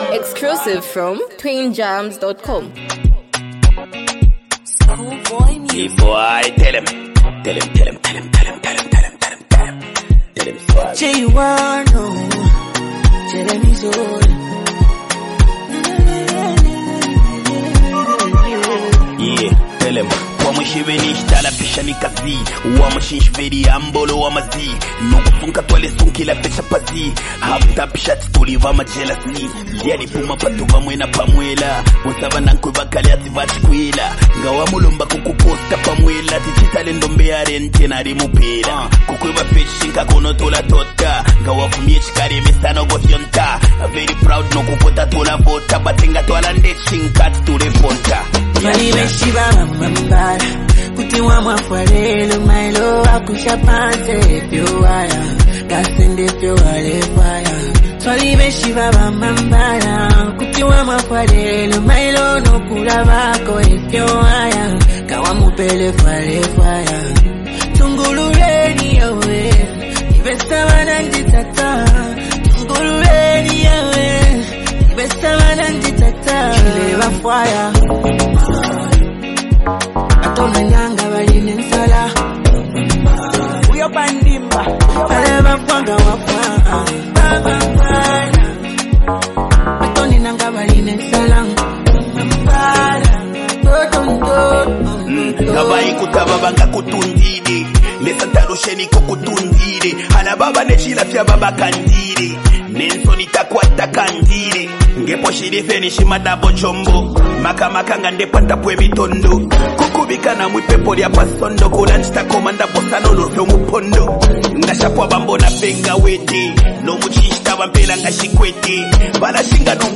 bold and energetic track
confident and assertive delivery